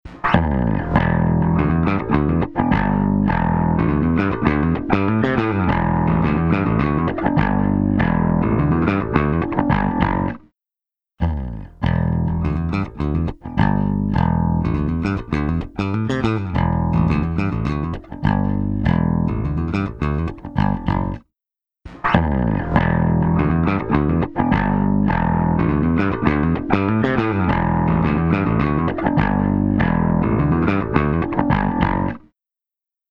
パワフルなディストーション＆サチュレーション・エンジン
CrushStation | Bass | Preset: VHS Tape
CrushStation-Eventide-Bass-VHS-Tape.mp3